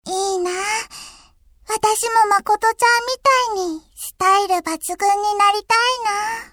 ＣＶ